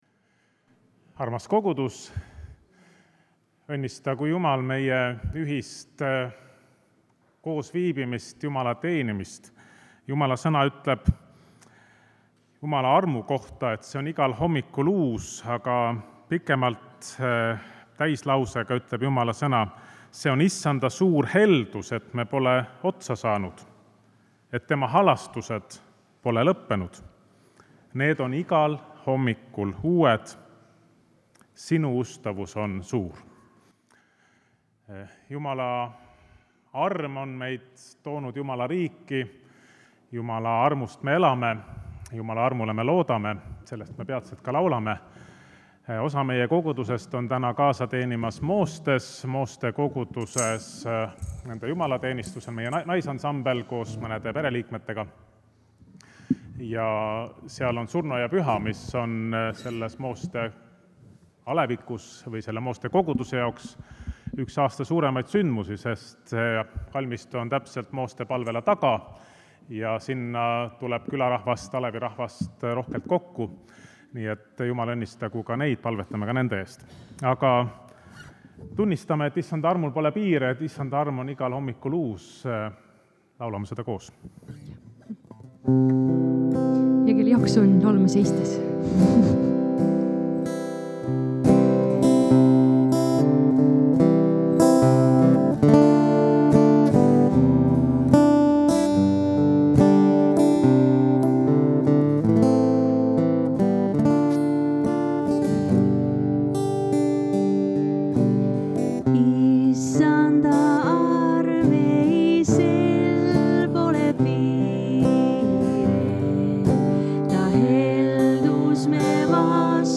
Pühakirja lugemine: Mt 6:24-34 Tunnistus
Jutlus